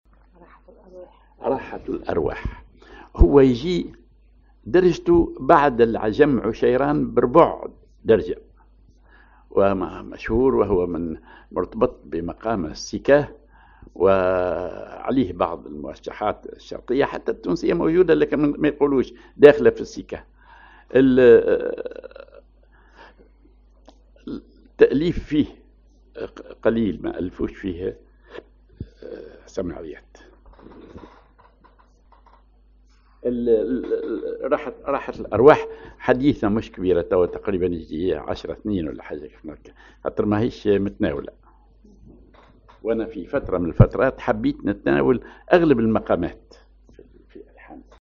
Maqam
سماعي ثقيل